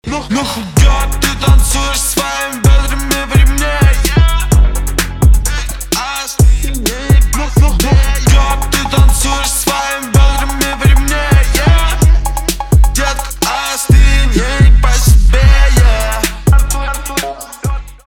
русский рэп
басы